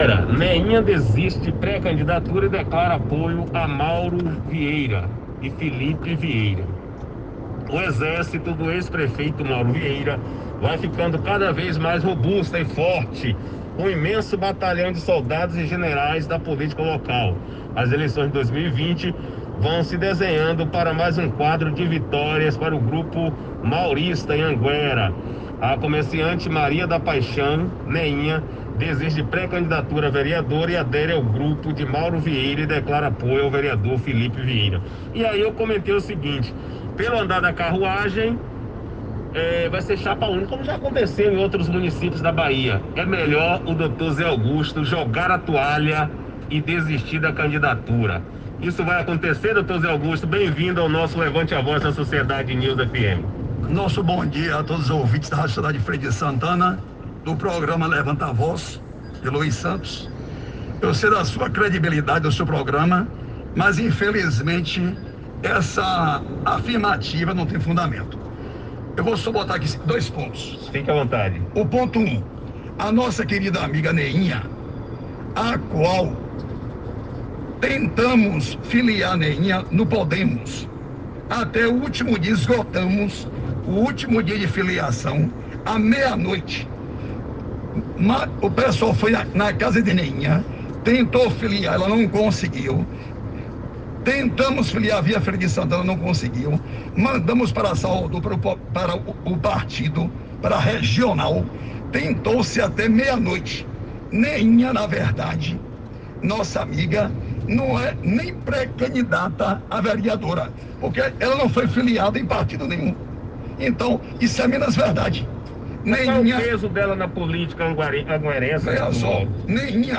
usando o direito de resposta na radio Sociedade News no programa Levante a Voz, disse ter esperança de reverter essa baixa no seu grupo.